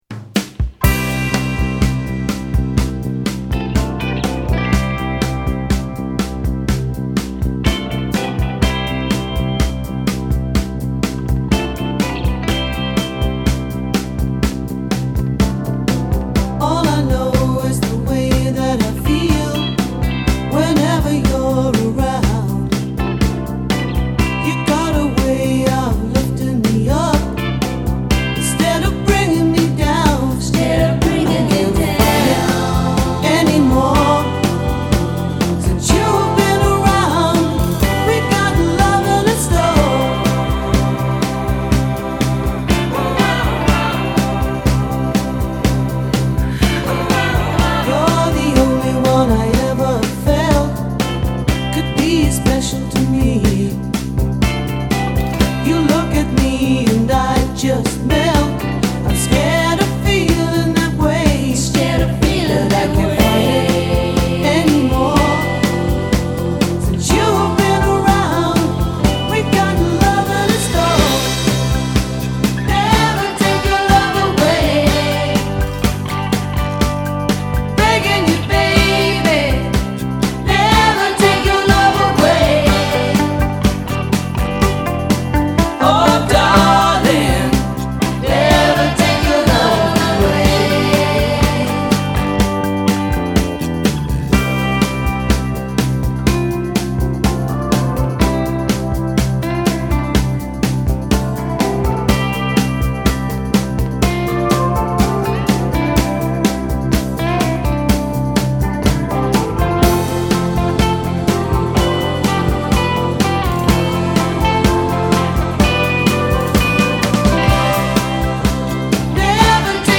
Again, the harmonies!